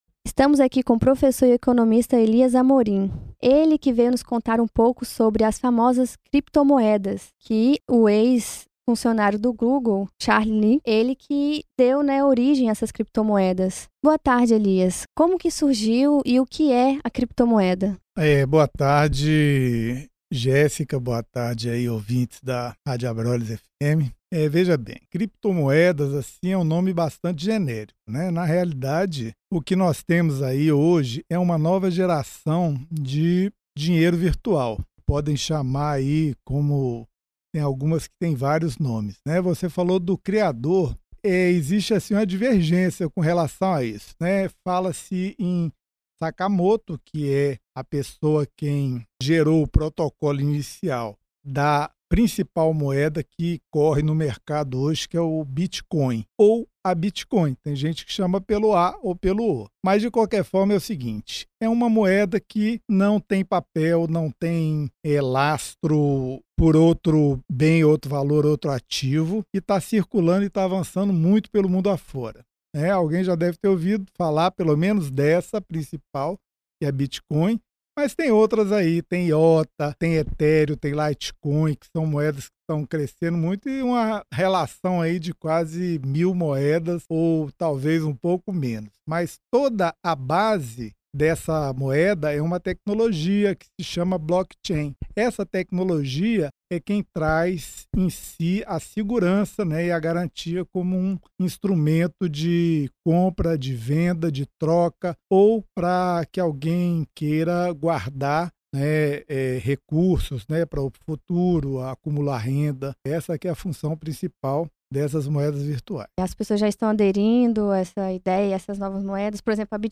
• Entrevistas